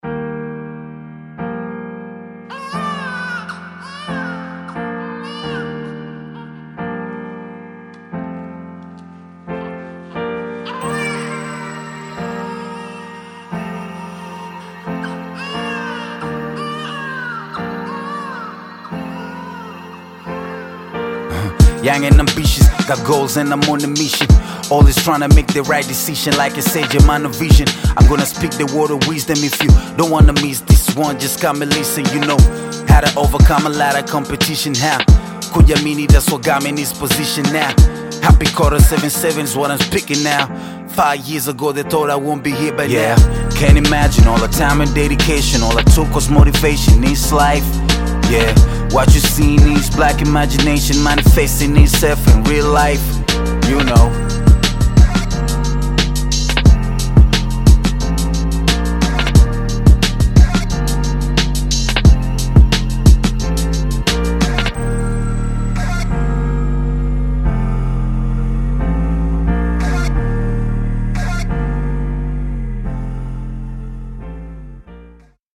Tanzanian bongo flava artist, rapper, singer, and songwriter
This is a short chorus
and if you’re a hip-hop fan